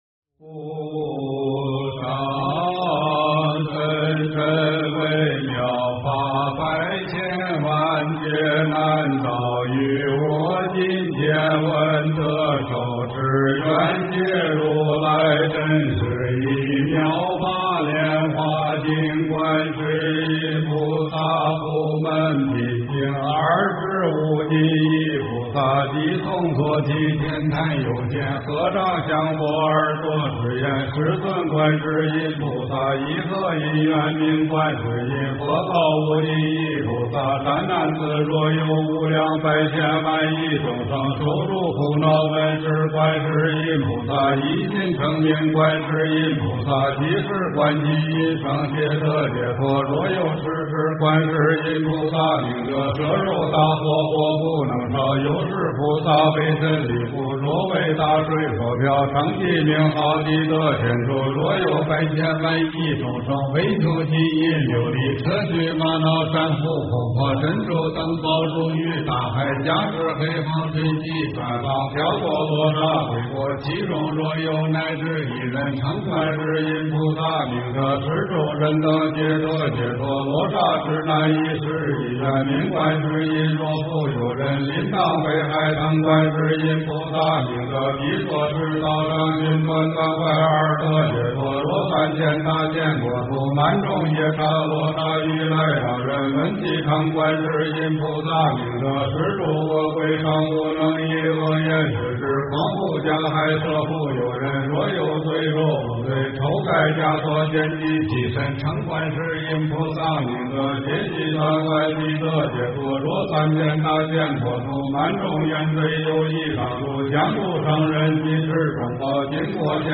观世音菩萨普门品（快诵）
诵经